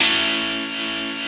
ChordA.wav